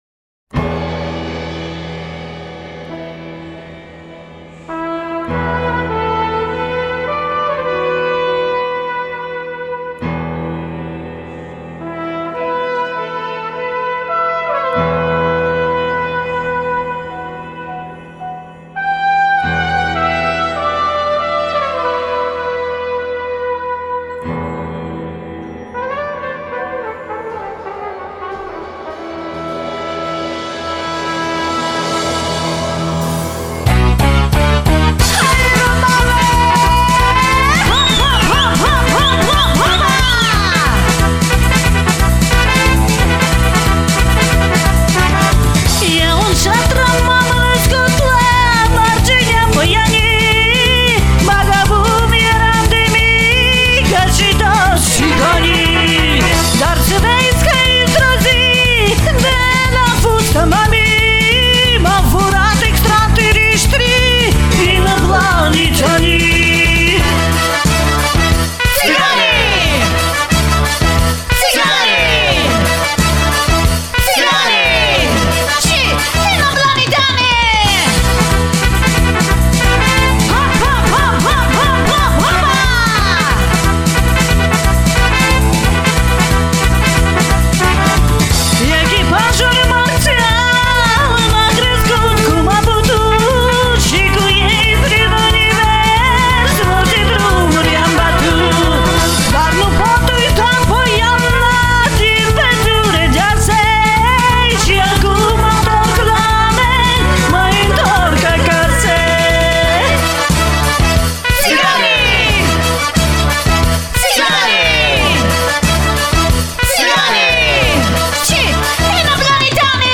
P.S. художественный свист